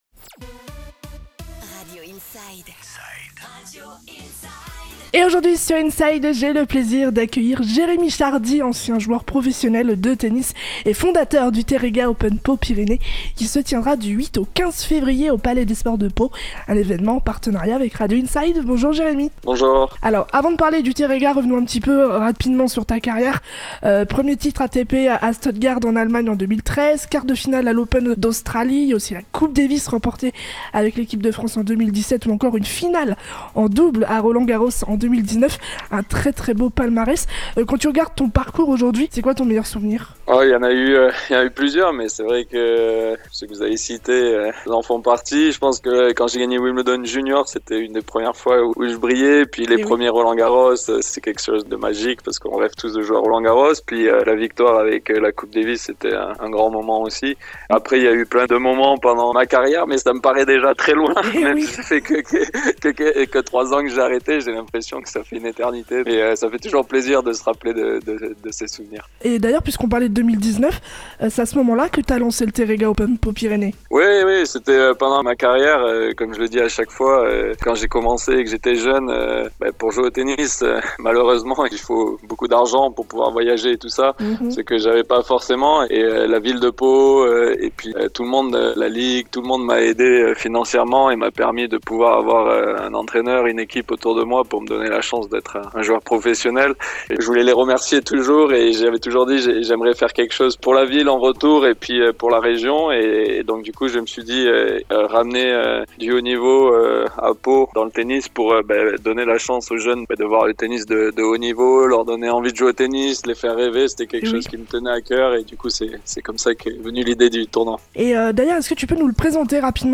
Interview de Jérémy Chardy "Open Pau Pyrénées 2026" à Pau, sur Radio Inside